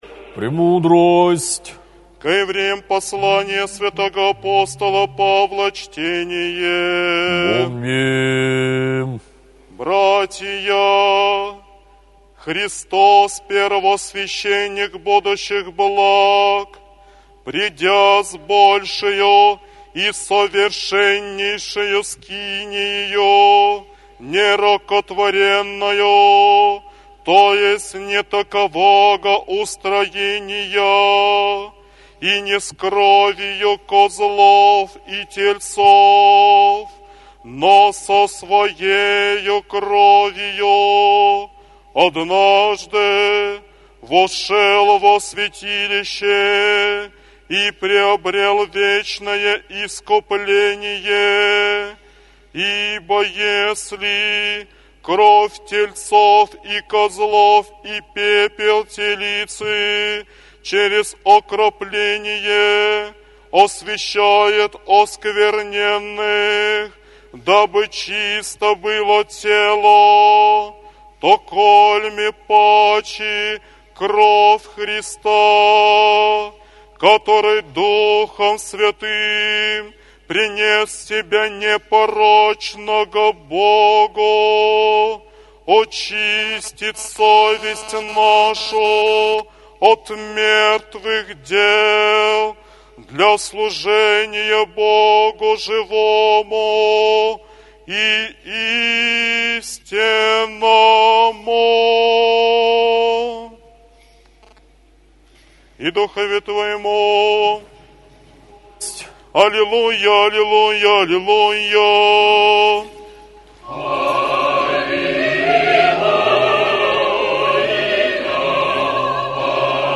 апостольское ЧТЕНИЕ